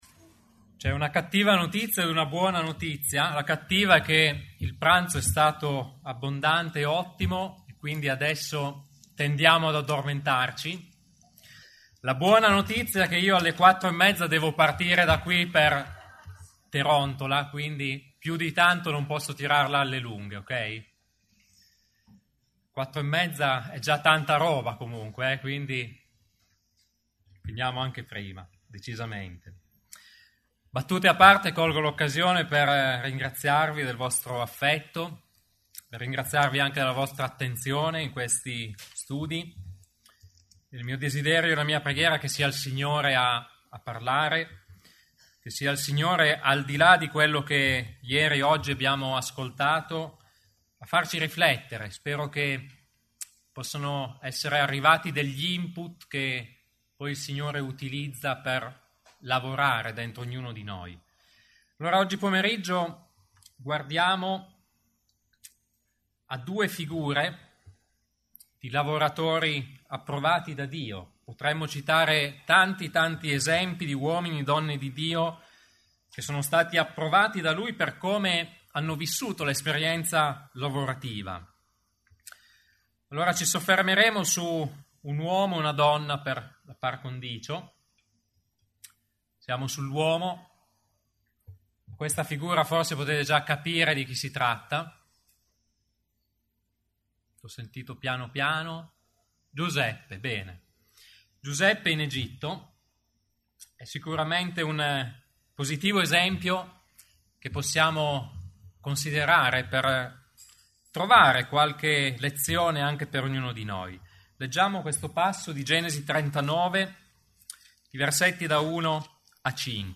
Tipo Di Incontro: Serie studi